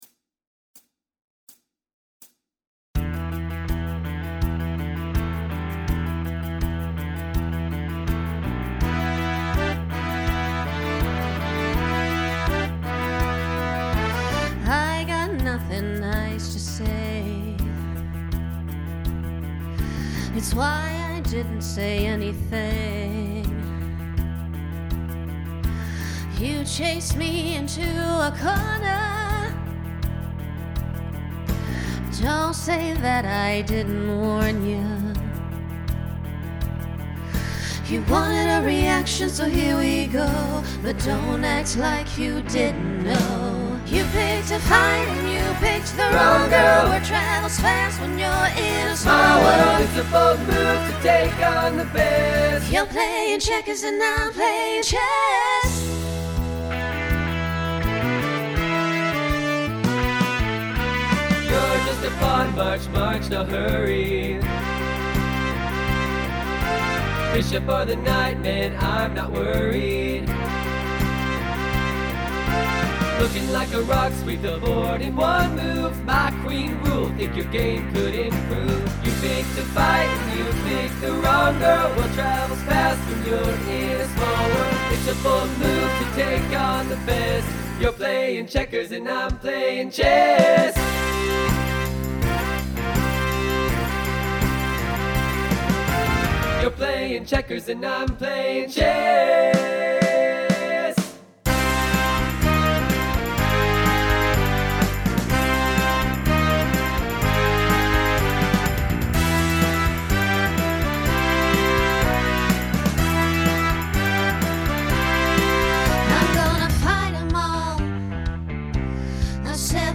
Starts with a treble duet, then TTB, then SSA, then SATB.
Genre Country , Rock
Transition Voicing Mixed